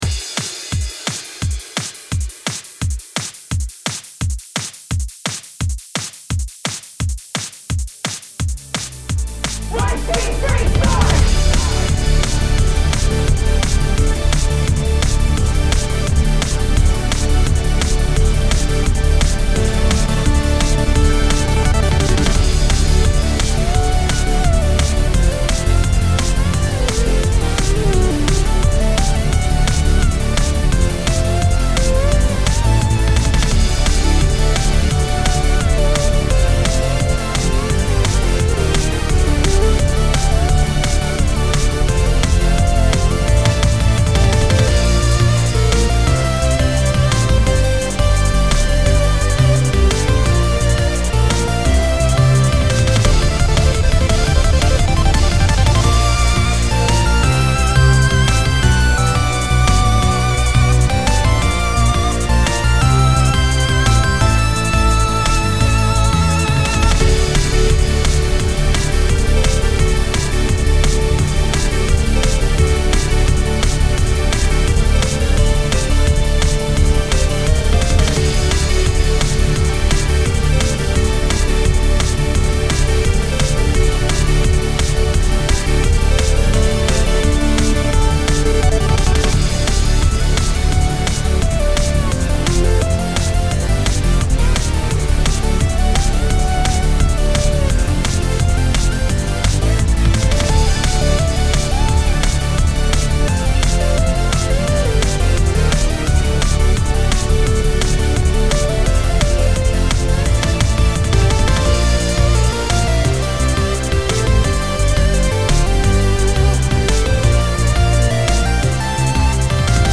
172bpm electronic dance music